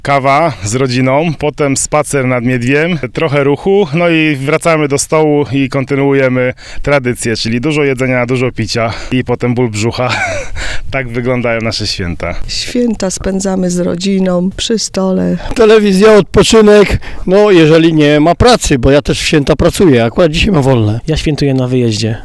jak spedzamy swieta sonda.mp3